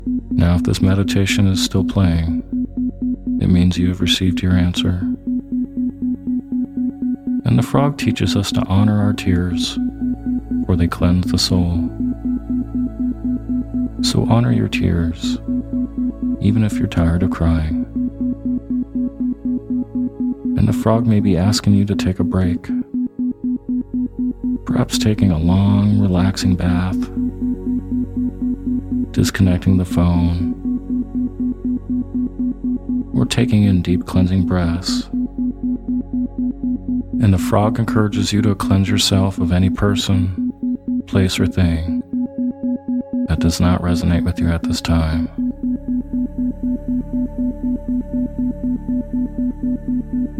Sleep Hypnosis For Listening To Your Inner Voice With Isochronic Tones
In this brief 12 min meditation, I’ll be guiding to visualize yourself connecting to your intuition or inner voice.